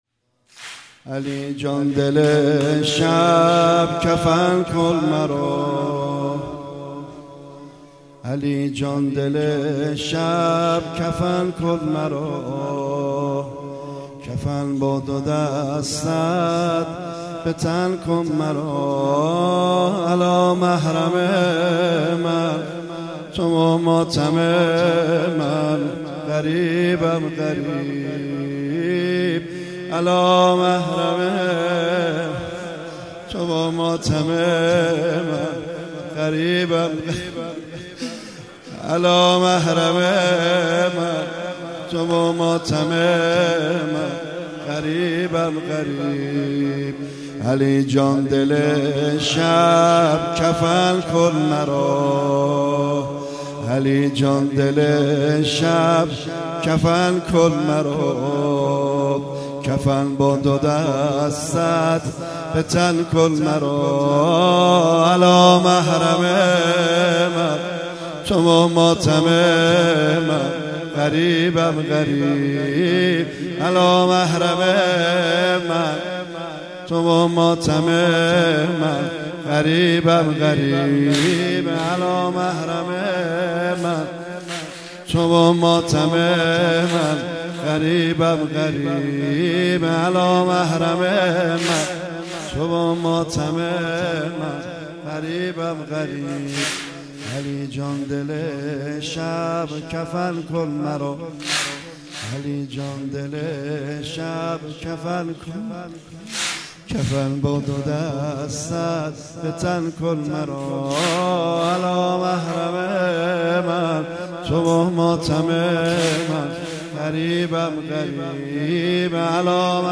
مداحی و نوحه
[واحد]